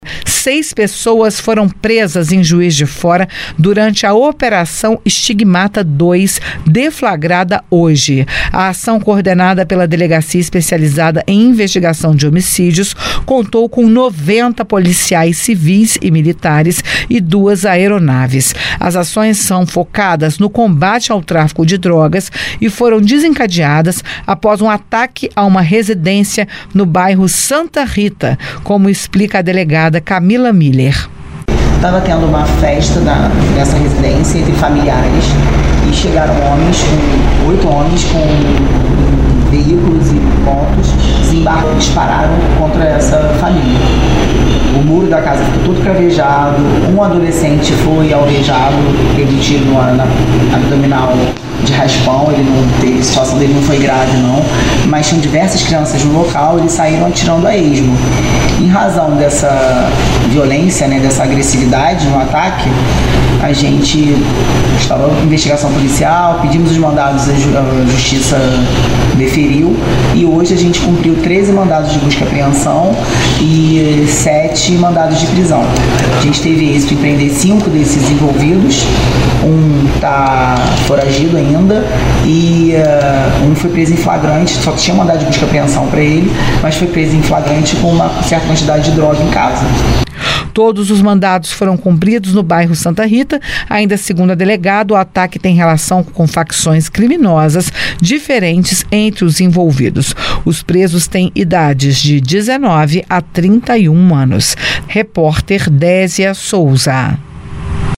Coletiva-PCMG-Operacao-Stigmata-II.mp3